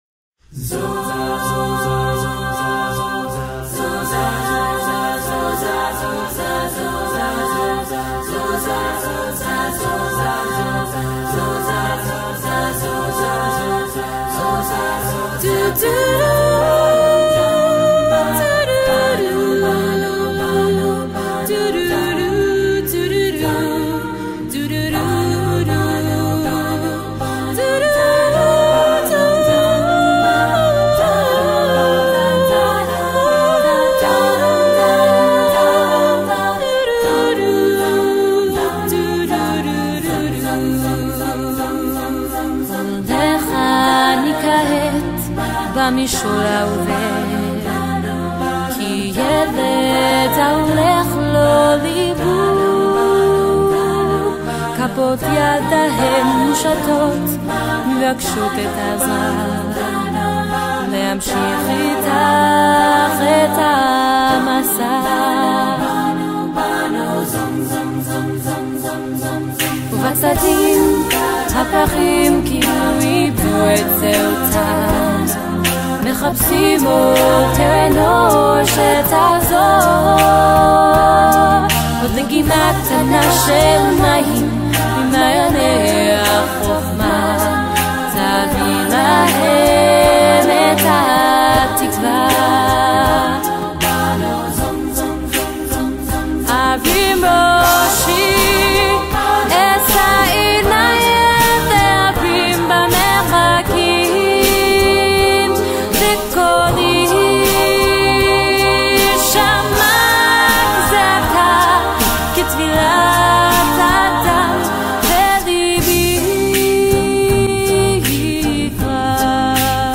Genre: Israeli
Contains solos: Yes